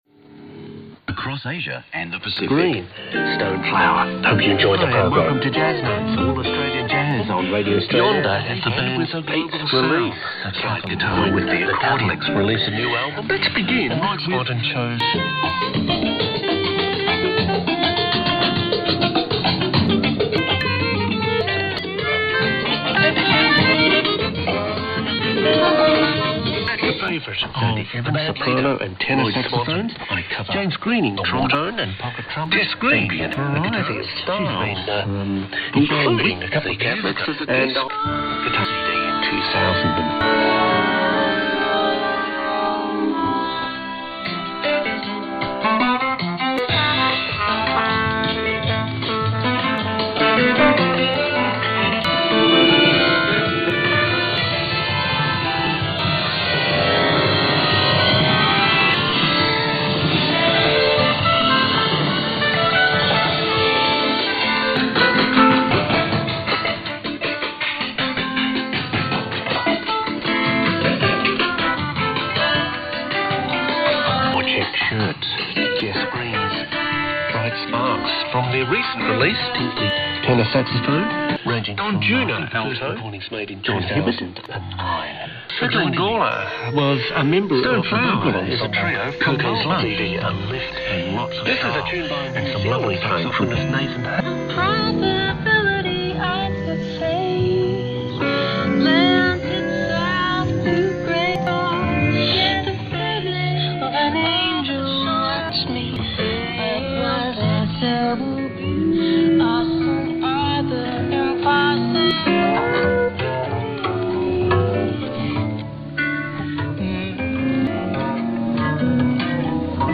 was created using samples extracted from a 30-minute 2013 shortwave broadcast
with draft sound files sent back and forth between Texas and California over the course of several days.